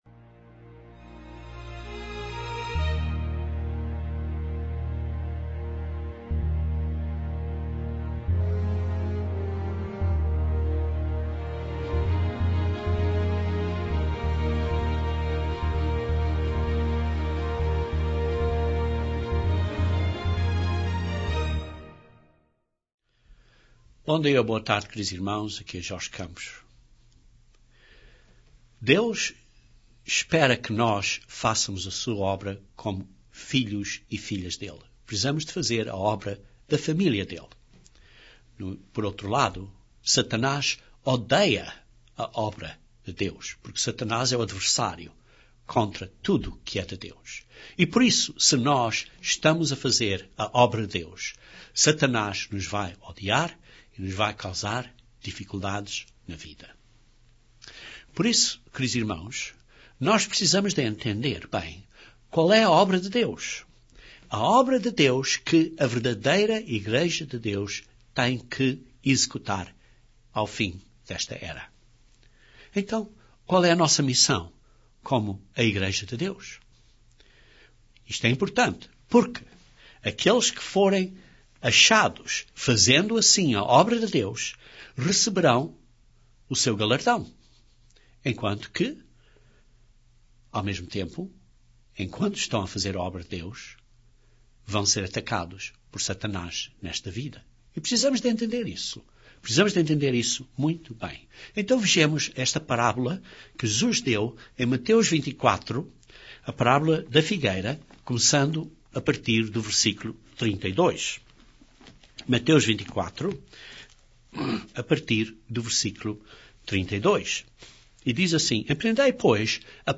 Este sermão sublinha esta ponto que Jesus ilustrou na parábola do bom servo (Mat:24:46).